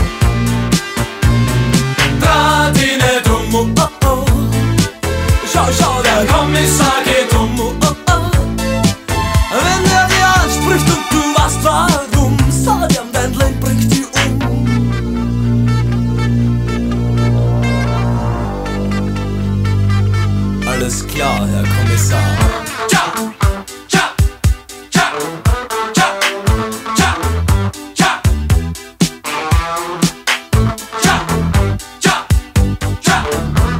Gattung: Stimmungs Hit
Besetzung: Blasorchester
als Arrangement für Blasorchester mit Gesang